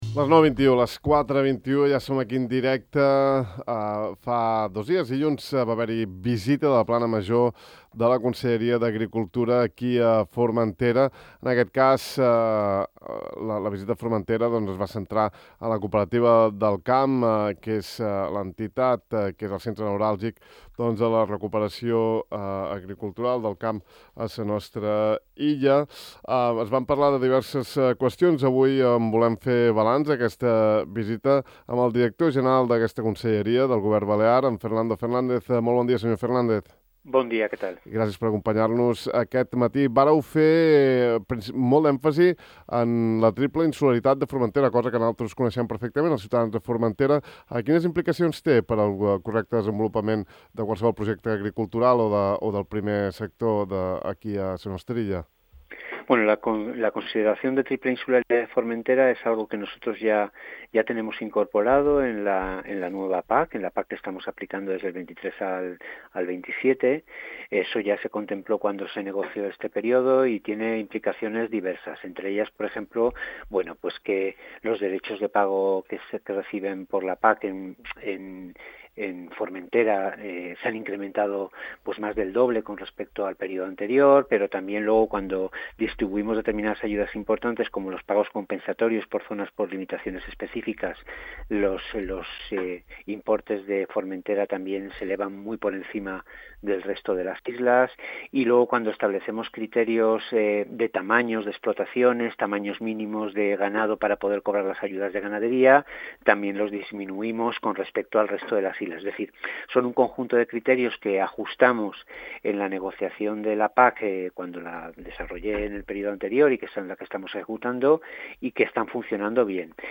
Els hidrants (punts d’abastiment d’aigua de rec) que no s’han emprat durant els darrers anys a Formentera es retiraran i se n’instal·laran de nous en camps que necessitin el regadiu. Així ho ha afirmat a Ràdio Illa Fernando Fernández, director general d’Agricultura, Ramaderia i Desenvolupament Rural del Govern balear, que ha destacat que la meitat dels 81 hidrants de la comunitat de la nostra illa no han fet servir aquesta infraestructura el darrer lustre.